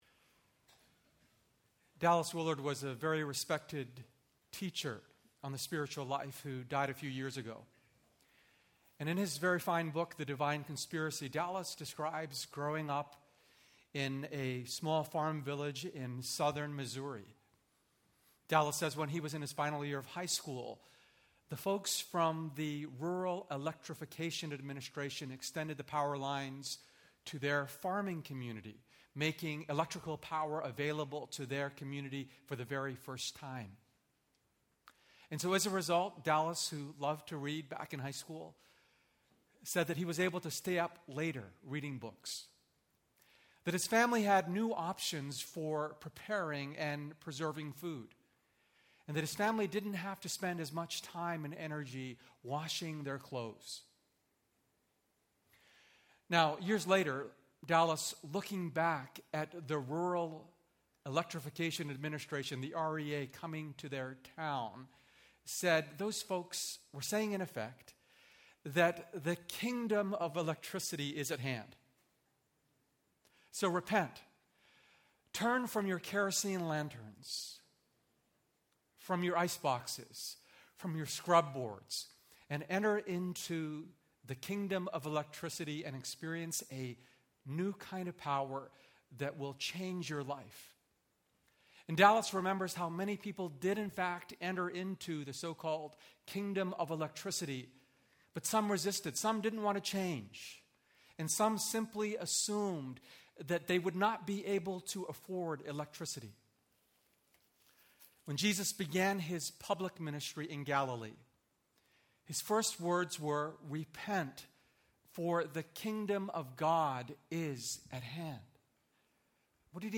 public ios_share Tenth Church Sermons chevron_right Thy Kingdom Come Feb 19, 2017 00:00 forum Ask episode play_arrow Play view_agenda Chapters auto_awesome Transcript info_circle Episode notes Matthew 6:9-10